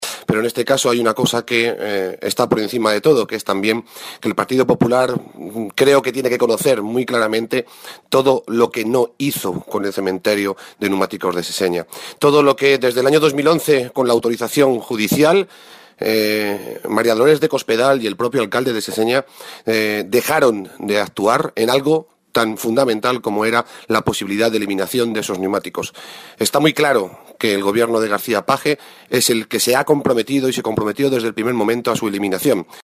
El presidente del Grupo Parlamentario Socialista en las Cortes de Castilla-La Mancha, Rafael Esteban, ha reiterado el compromiso de su grupo para que se pueda conocer "en profundidad" todo lo acontecido en el cementerio de neumáticos de Seseña.
Cortes de audio de la rueda de prensa